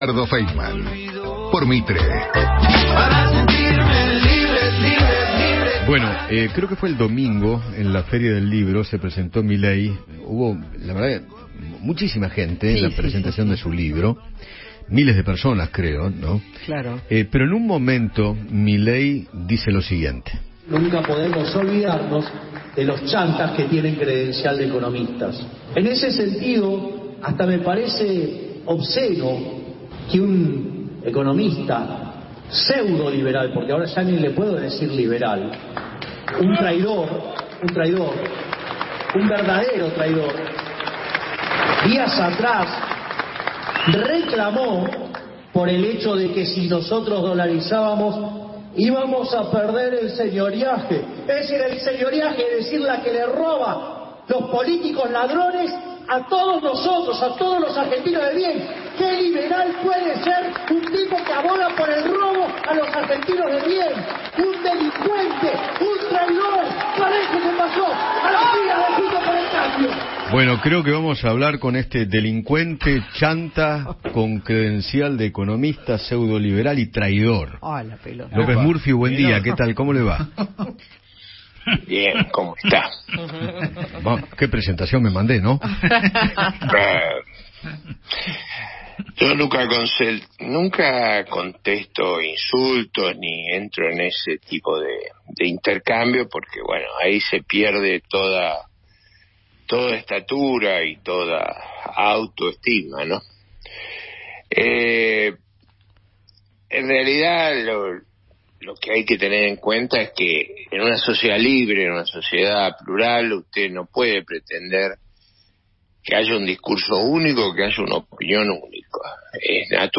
Eduardo Feinmann conversó con Ricardo López Murphy, precandidato a jefe de gobierno por “Republicanos Unidos”, tras los dichos del líder de la Libertad Avanza.